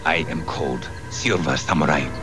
From X-Men: The Animated Series.
samuri_sound_1.wav